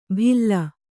♪ bhilla